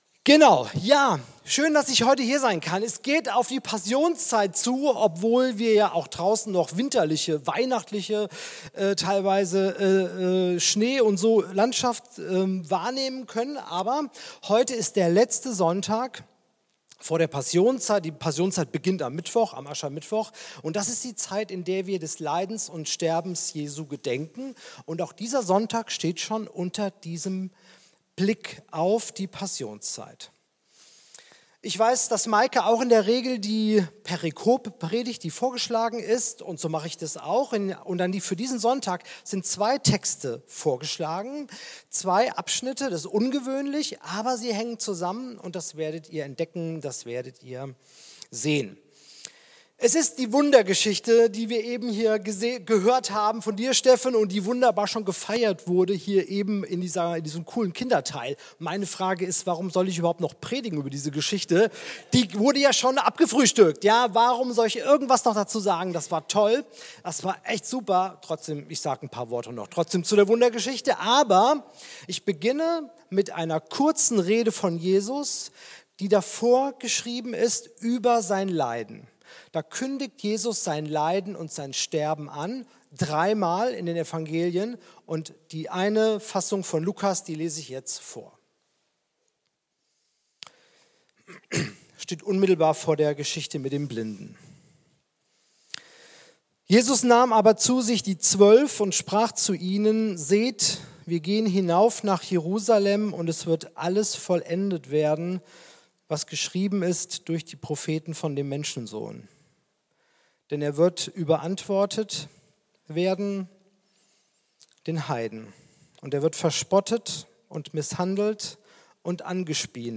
Jesus heilt einen Blinden ~ Christuskirche Uetersen Predigt-Podcast Podcast